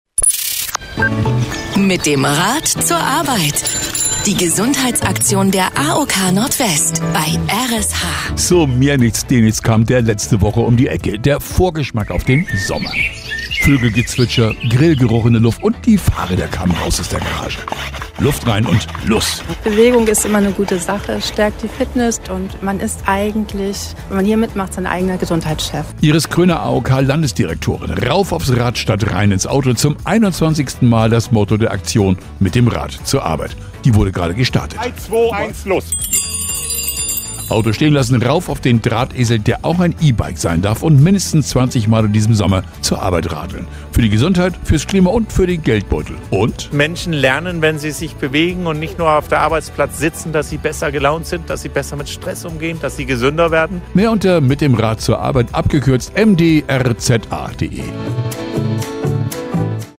R.SH-Sendebeitrag 4